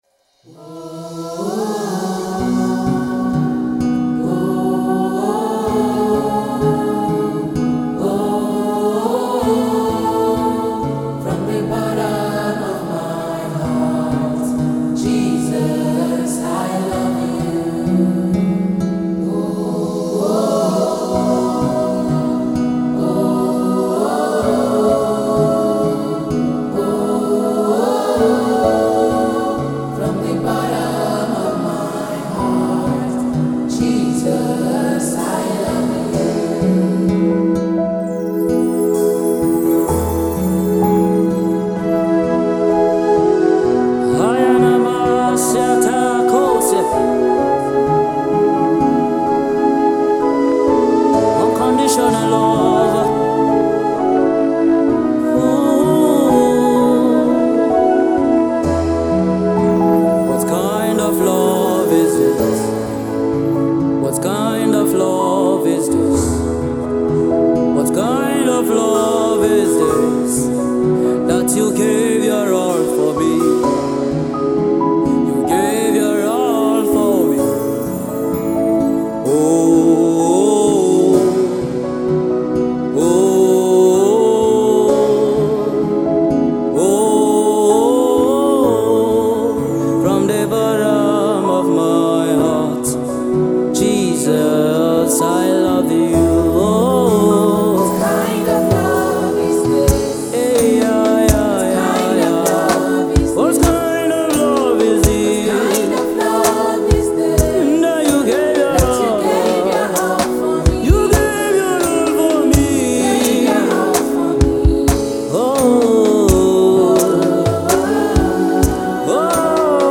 gospel
soulful vocals
powerful delivery